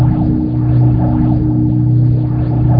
_TELEPOR.mp3